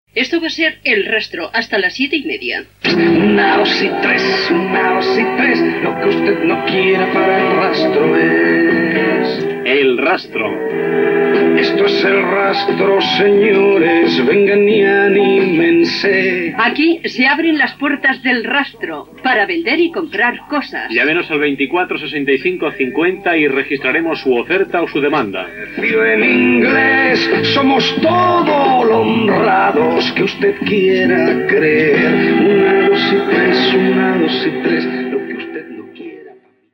Presentació inicial del programa, telèfon de participació